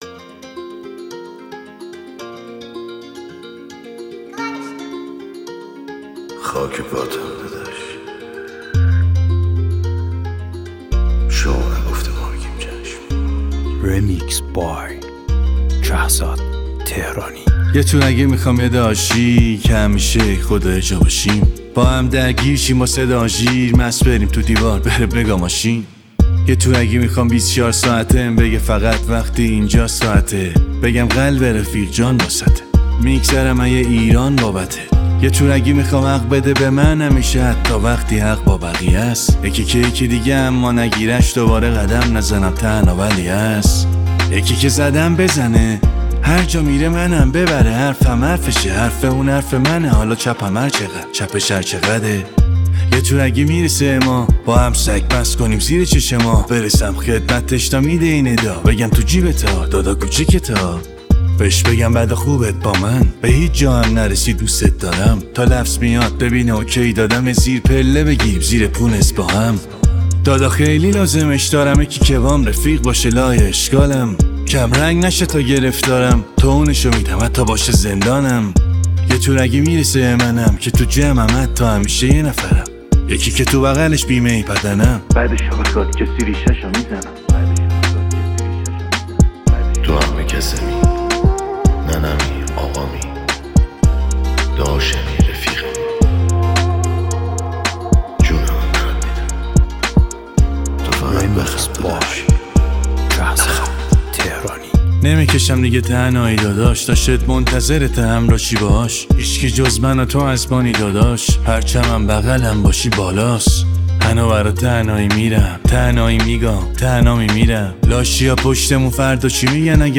کلی حس داره این موزیک، مخصوص دور دور شبانه.